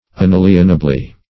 -- Un*al"ien*a*bly , adv.
unalienably.mp3